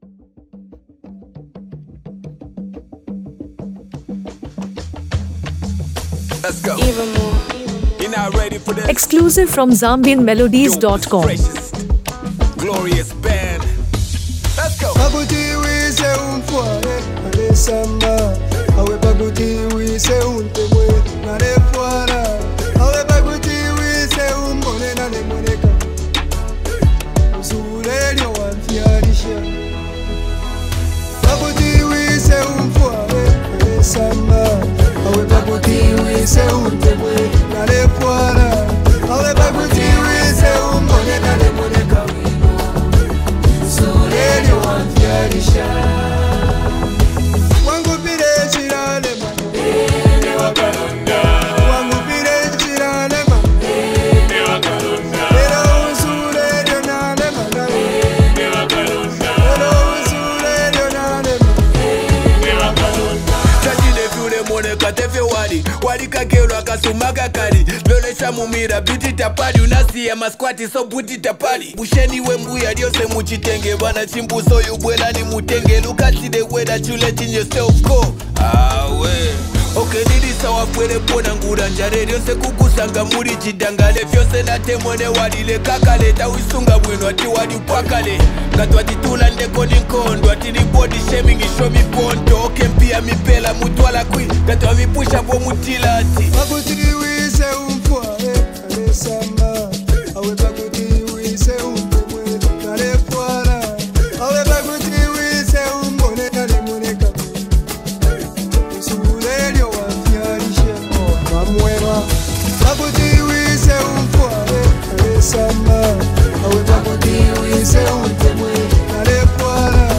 live instrumental touch